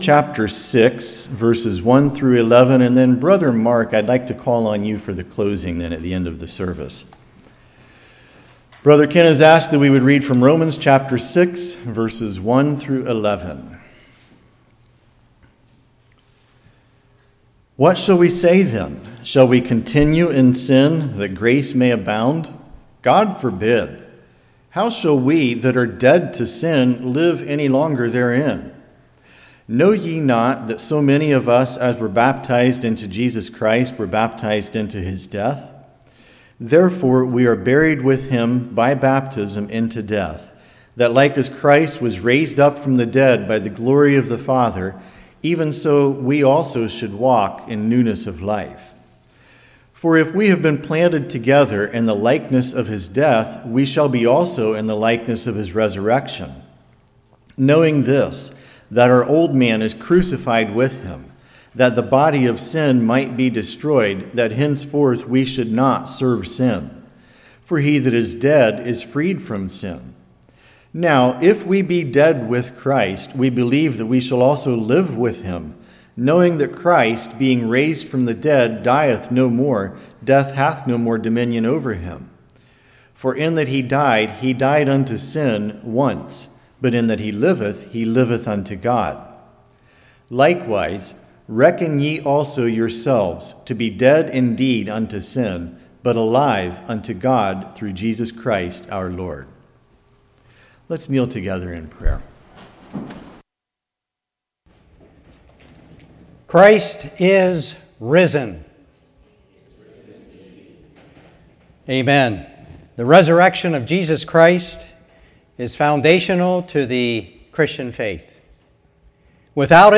Service Type: Evening